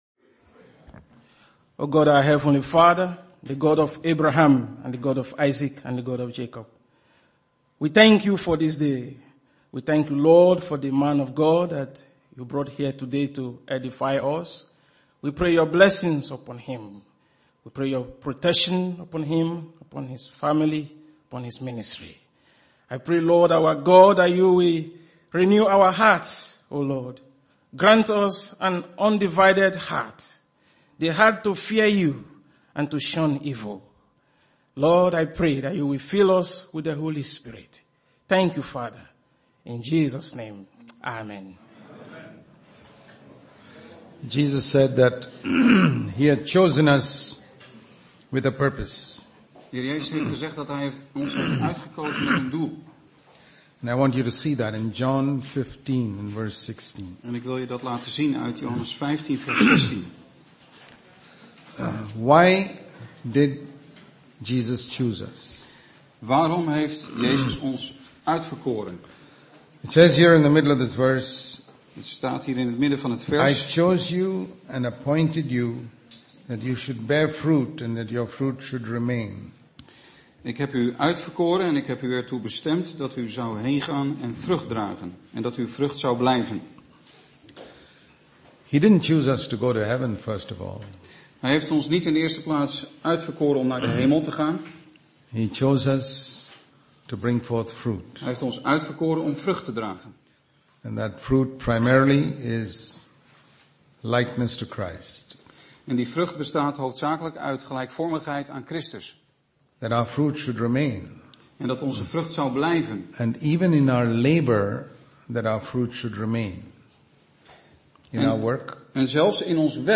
Een preek over 'Een instrument in Gods hand'.
(met vertaling)